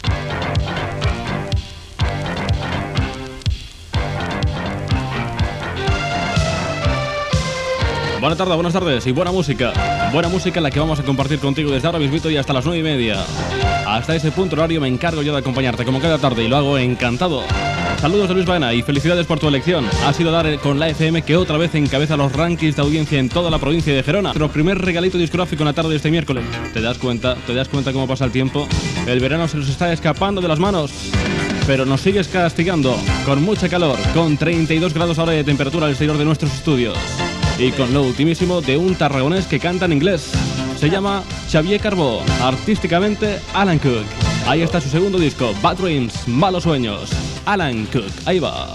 Temperatura, comentari i presentació d'un tema musical
Musical
FM
Fragment extret de l'arxiu sonor de Ràdio Platja d'Aro